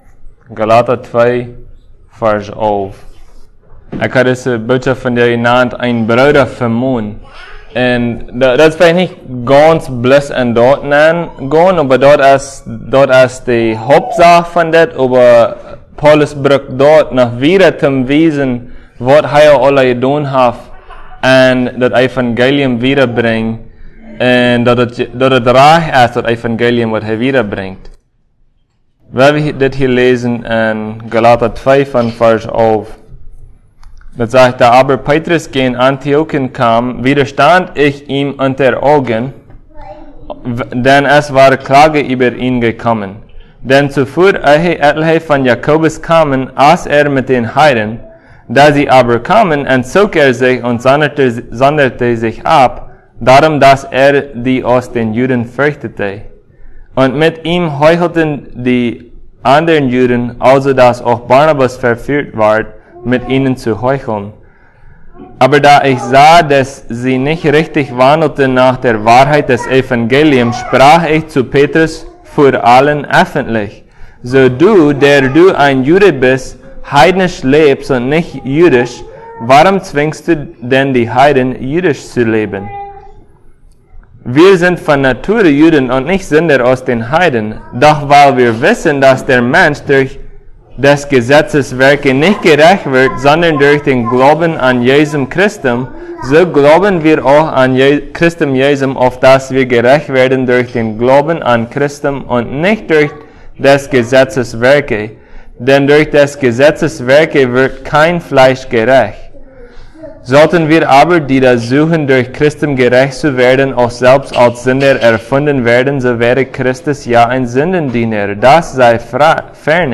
Passage: Galatians 2:11-21 Service Type: Sunday Plautdietsch « The New and Better Covenant The Tabernacle